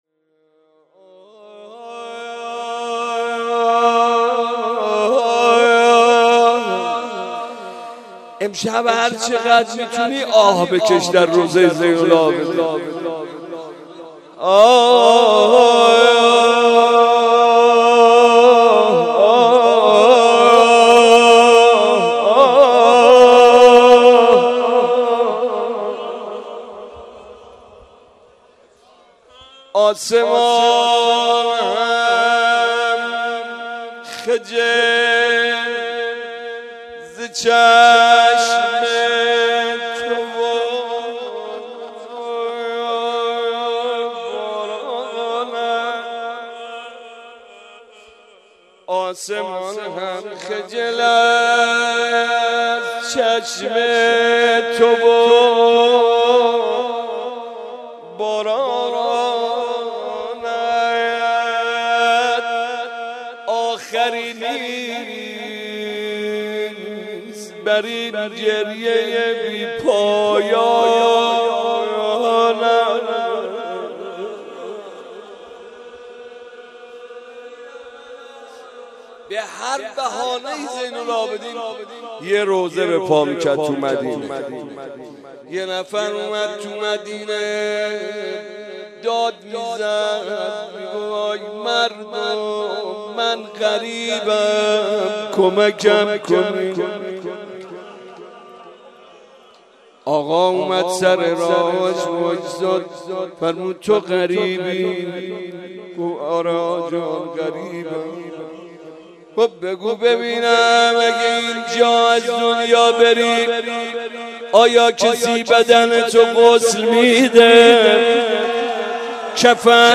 مراسم شب چهارم ماه مبارک رمضان با مداحی
مناجات
روضه